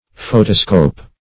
Search Result for " photoscope" : The Collaborative International Dictionary of English v.0.48: Photoscope \Pho"to*scope\, n. [Photo- + -scope.] (Physics) Anything employed for the observation of light or luminous effects.